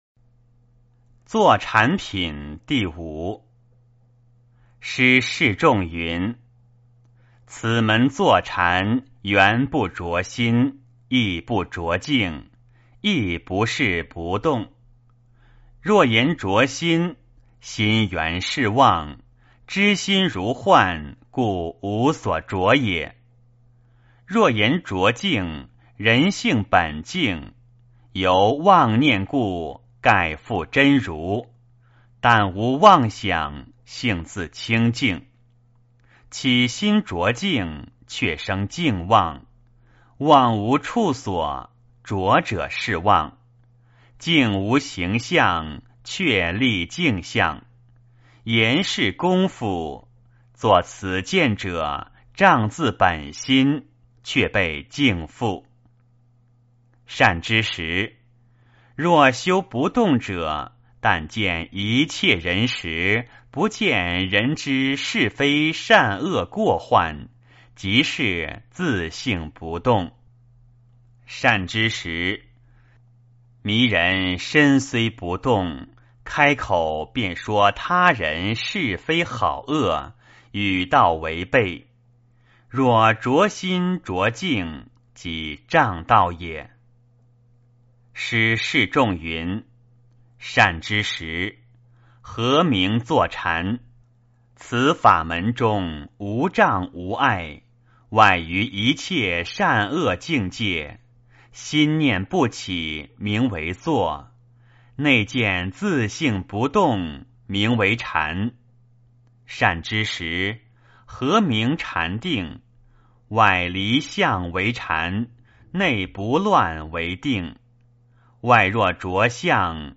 坛经（坐禅品） - 诵经 - 云佛论坛